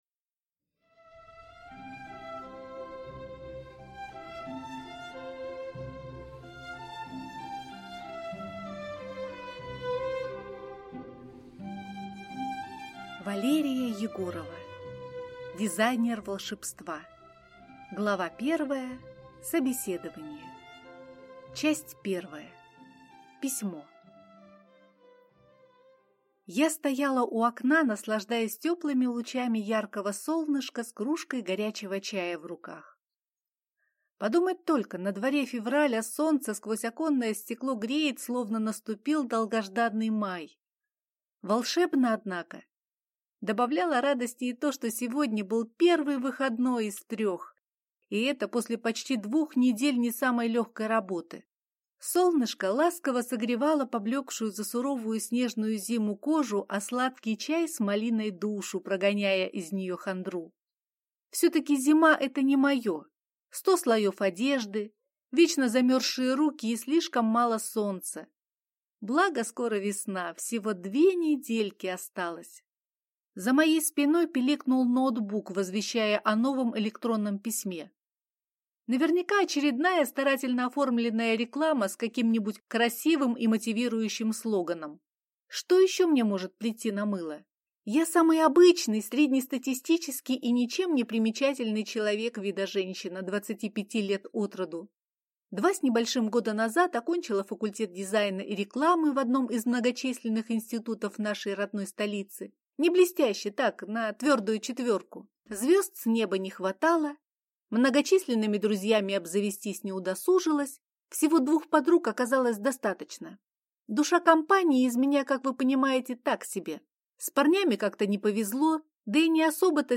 Аудиокнига Дизайнер волшебства | Библиотека аудиокниг